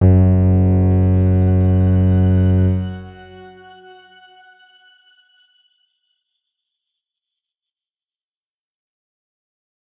X_Grain-F#1-mf.wav